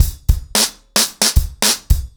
BlackMail-110BPM.21.wav